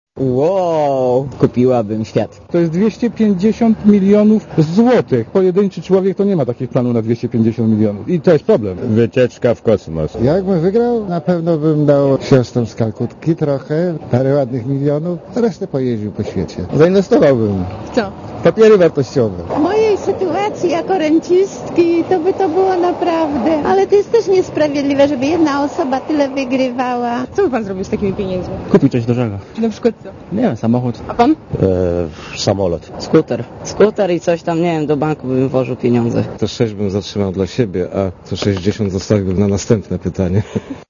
Zapytaliśmy mieszkańców Szczecina co zrobiliby z takimi pieniędzmi.